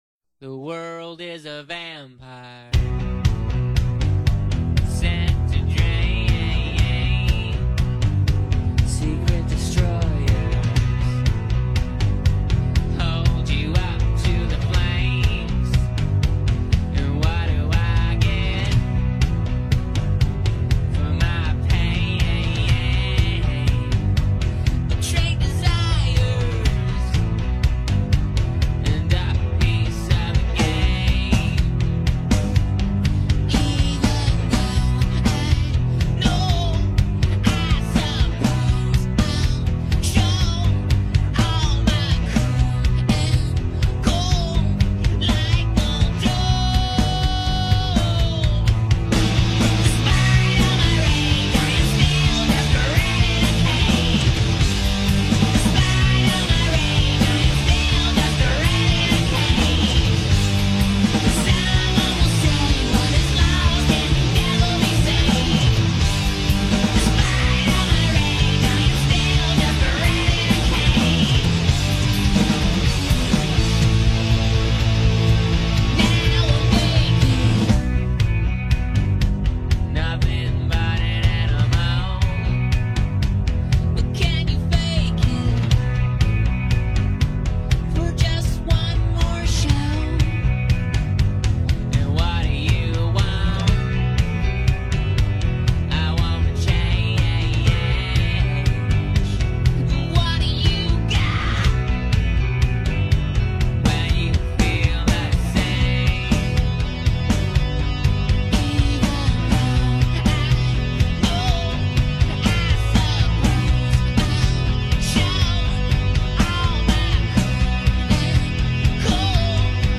(up half step)